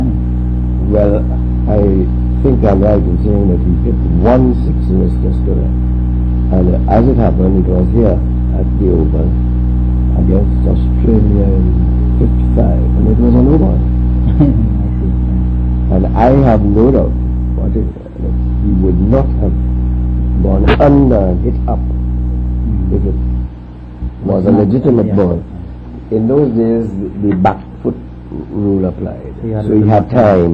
5 audio cassettes
The Oral and Pictorial Records Programme (OPReP)